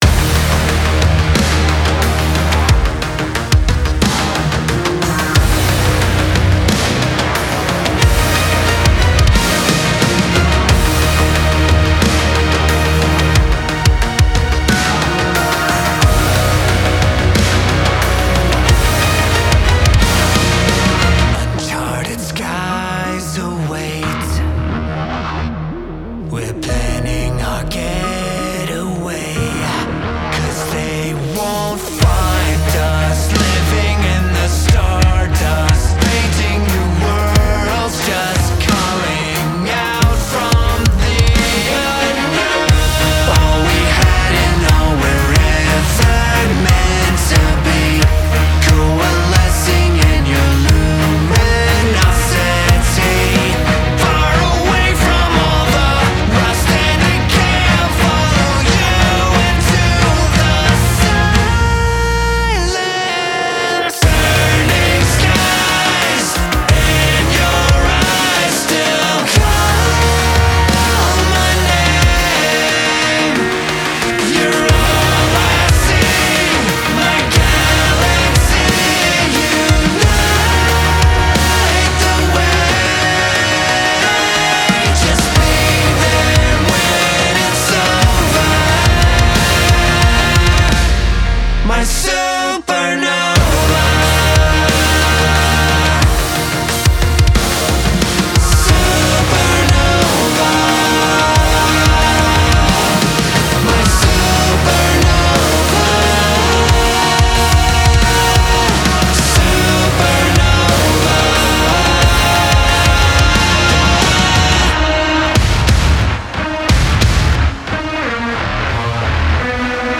• Жанр: Рок